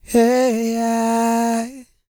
E-CROON 203.wav